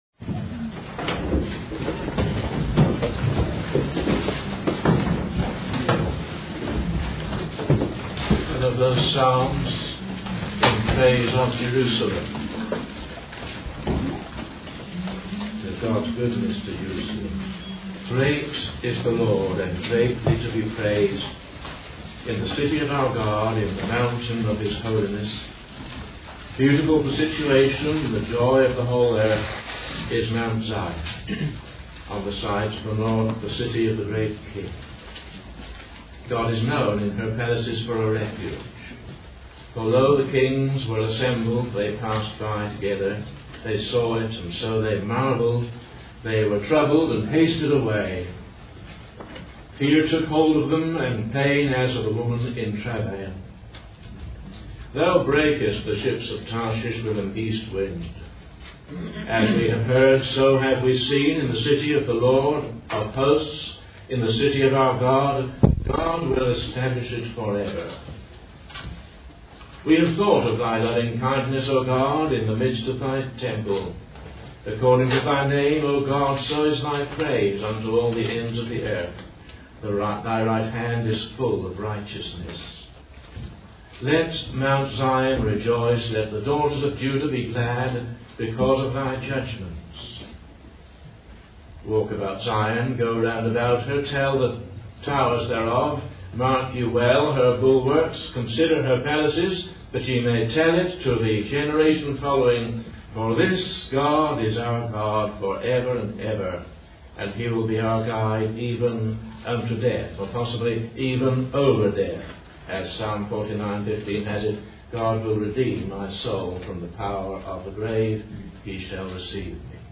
In this sermon, the speaker focuses on the story of David and how he made a mistake by not consulting God before carrying out his plans. The speaker emphasizes that sometimes we may think we are serving the Lord, but in reality, we are serving ourselves.